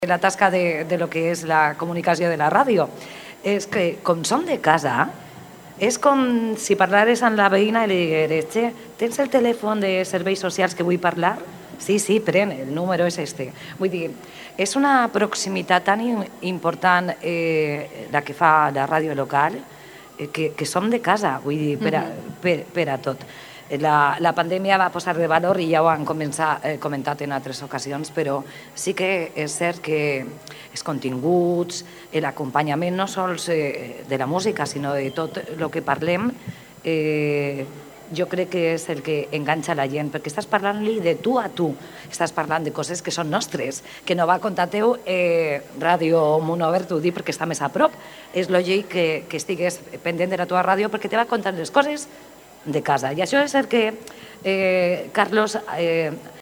L’acte va tindre lloc a la Plaça Manisses, junt al Palau Generalitat Valenciana, on les emissores presents disposaven d’un racó on estar i promocionar-se, compartint experiències amb companys i companyes d’altres emissores.